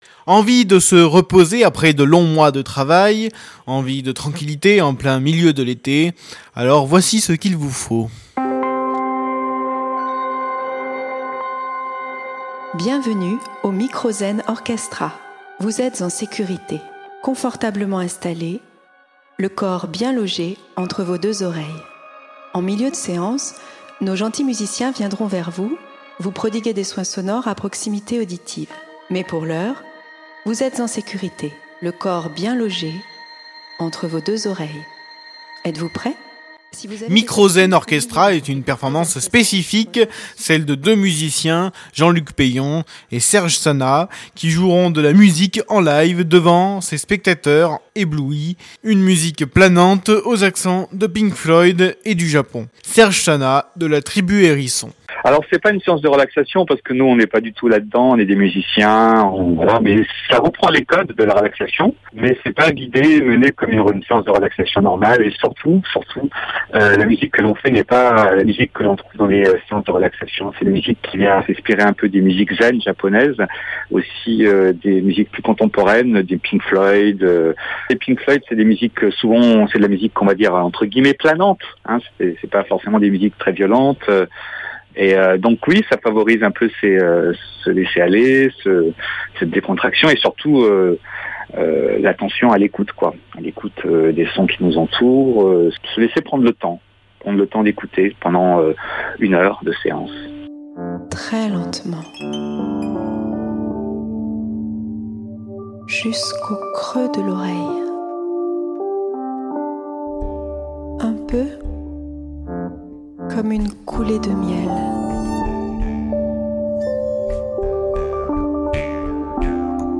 MZO. Lyon première interview